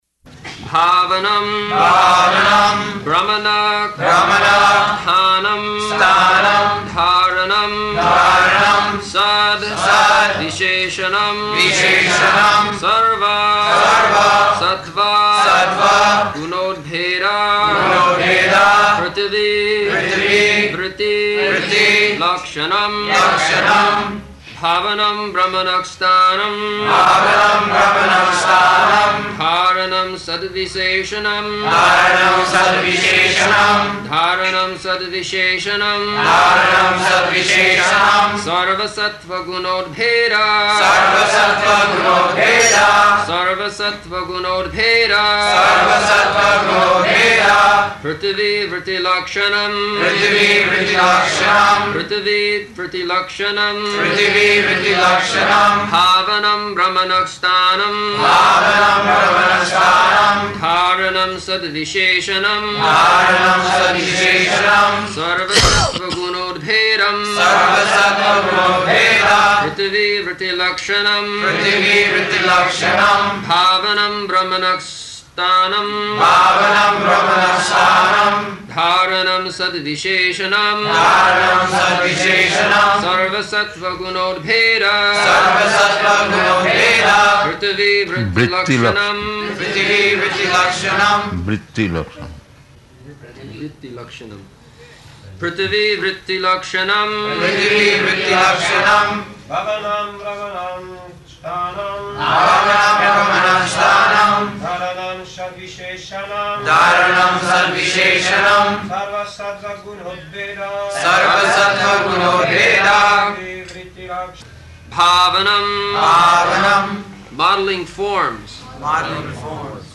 January 21st 1975 Location: Bombay Audio file
[devotees repeat] bhāvanaṁ brahmaṇaḥ sthānaṁ dhāraṇaṁ sad-viśeṣaṇam sarva-sattva-guṇodbhedaḥ pṛthivī-vṛtti-lakṣaṇam [ SB 3.26.46 ] Prabhupāda: [correcting pronunciation] Vṛtti-lakṣaṇam.